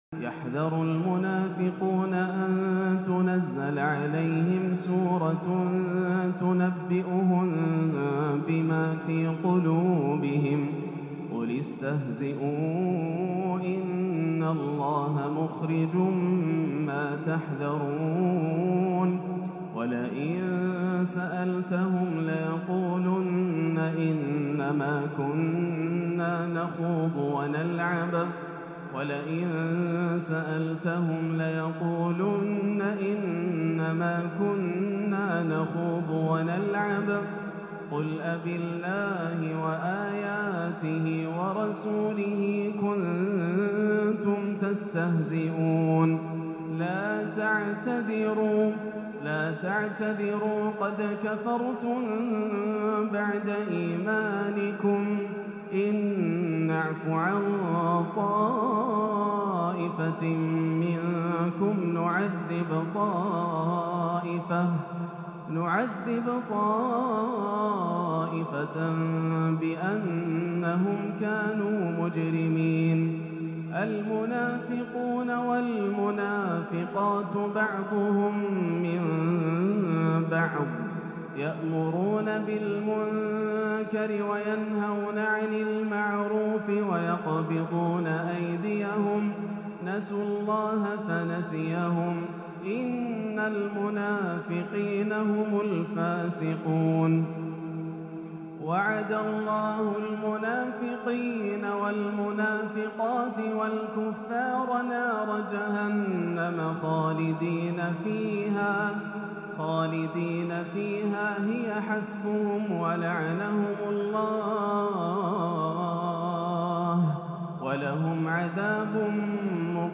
تلاوة حجازية حزينة من سورة التوبة - الأربعاء 9-2-1438 > عام 1438 > الفروض - تلاوات ياسر الدوسري